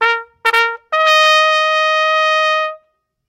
soundblocks_trumpet.ogg